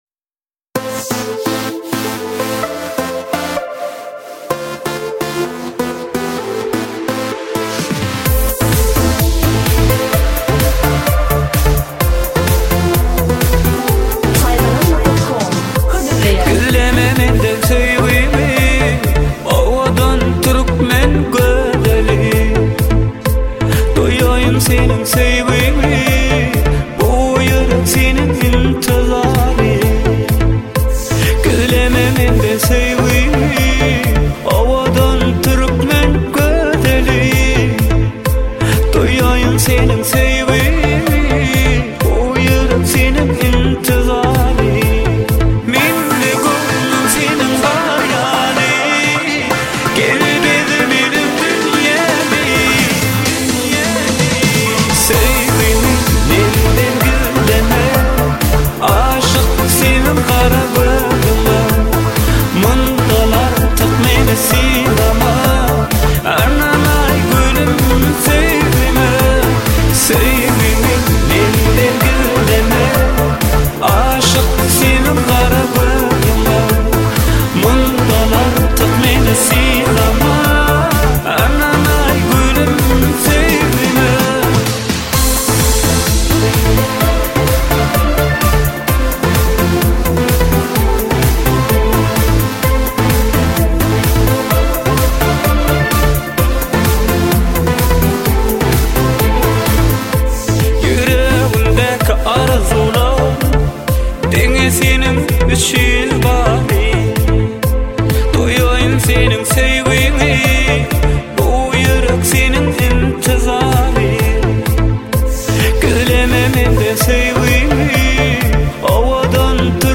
آهنگ ترکمنی شاد برای رقص